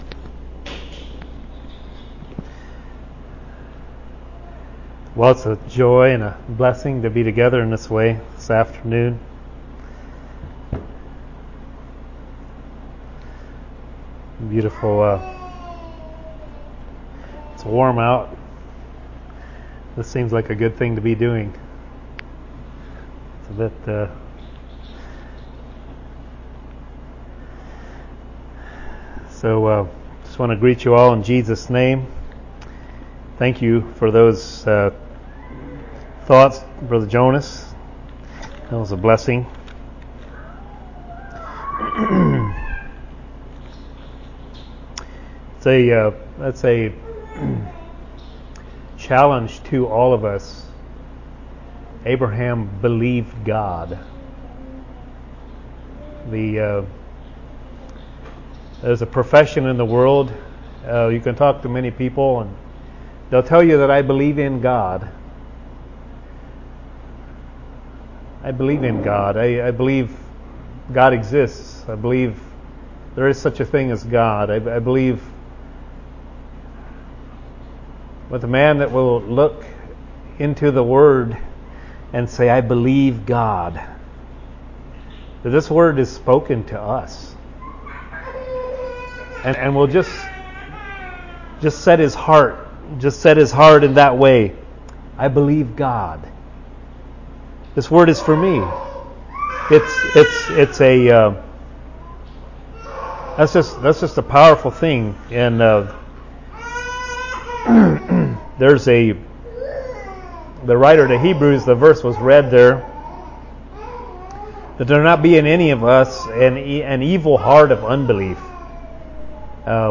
Listen to and download sermons preached in 2024 from Shelbyville Christian Fellowship.